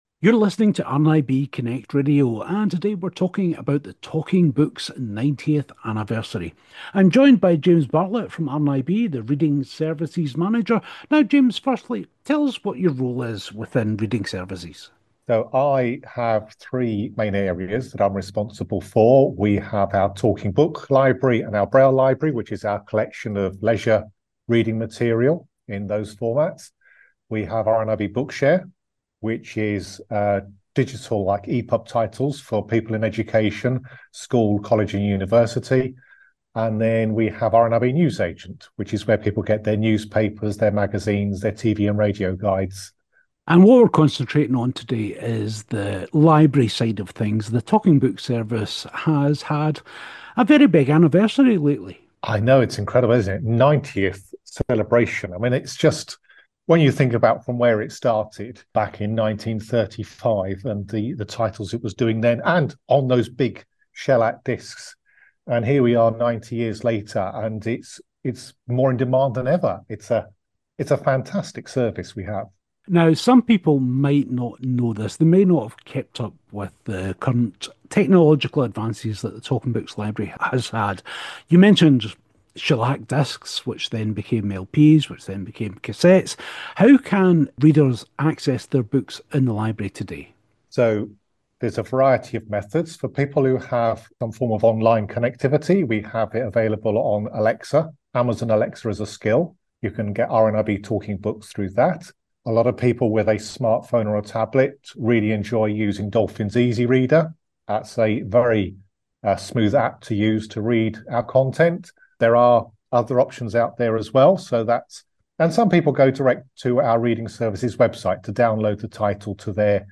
We also hear the foreword by Julian Fellowes.